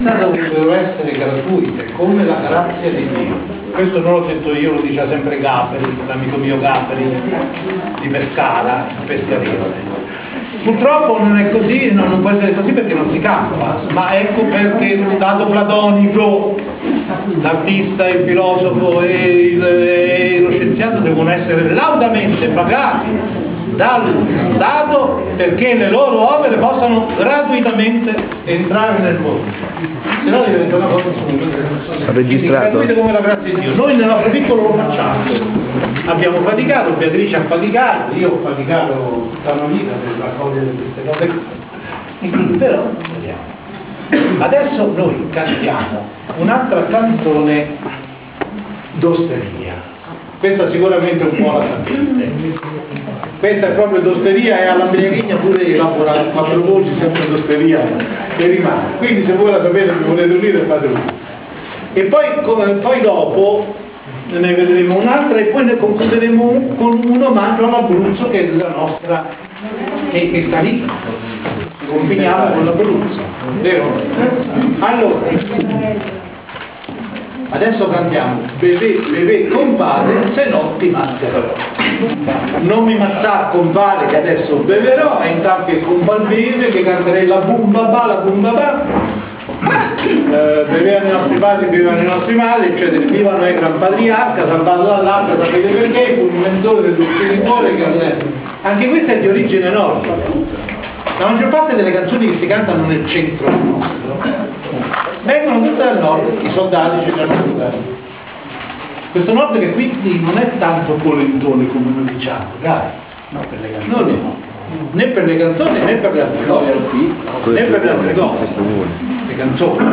Civico Museo d’Arte Moderna di Anticoli Corrado, sabato 13 ottobre 2007